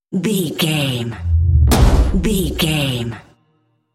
Dramatic hit deep scary trailer
Sound Effects
Atonal
heavy
intense
dark
aggressive
hits